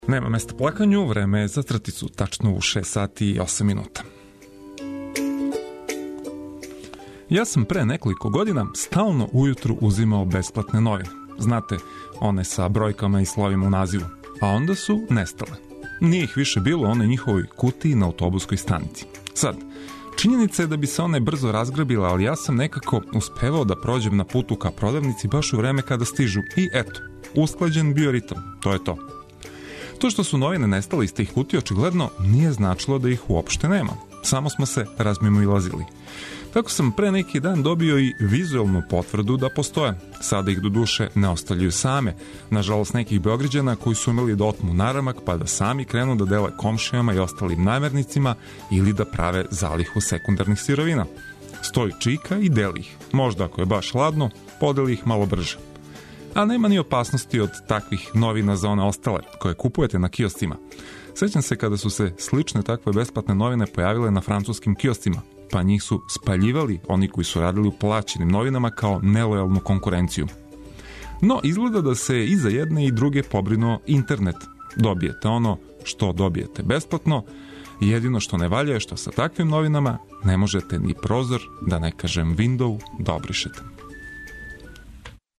И за једне и за друге овог јутра ћемо вам понудити добру музику и неке интересантне приче, као и најнужније информације!